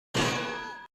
Падение наковальни
Наковальня-падение-звук-256-kbps.mp3